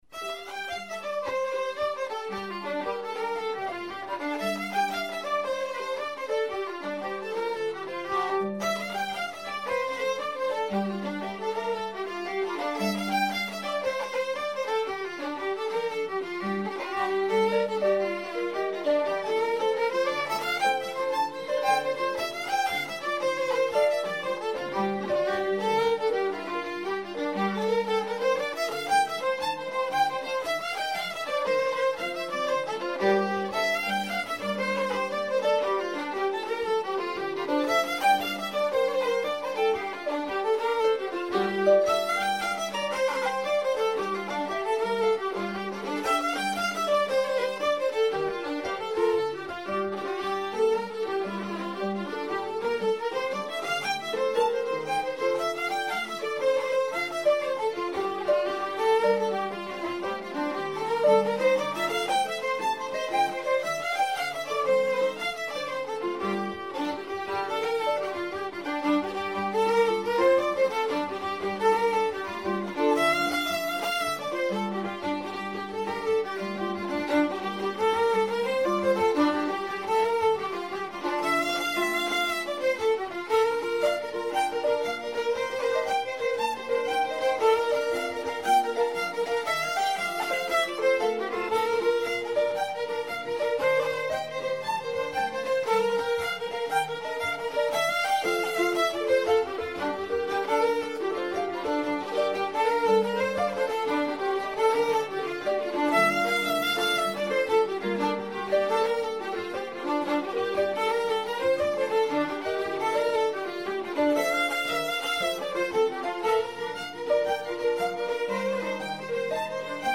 A great tune